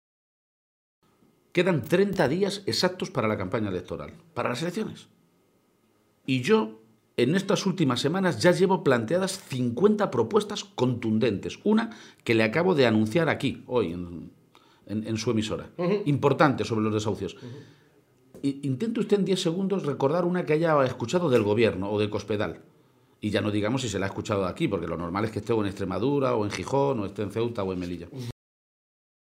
Entrevista a García-Page en la Cadena Ser